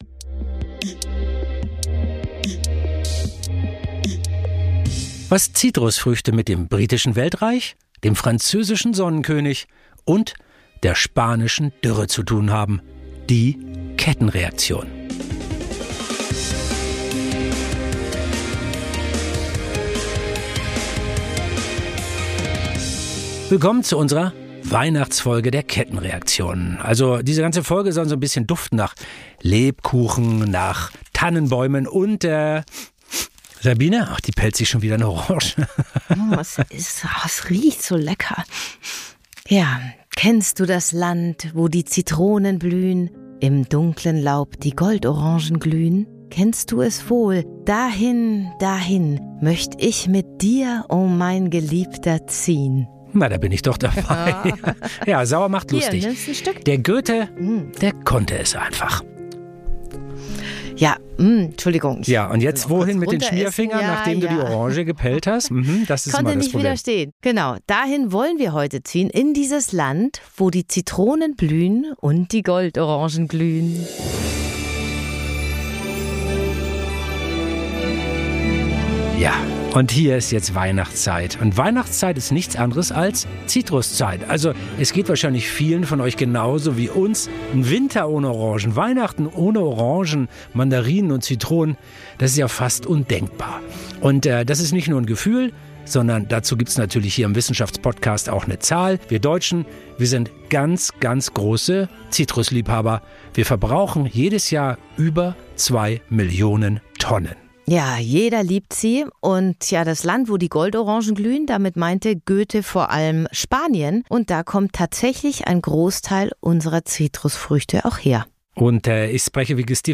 lassen sich von einem Agrar-Experten erklären, wie es auch mit Zitrusfrüchten ein frohes und nachhaltiges Fest werden kann.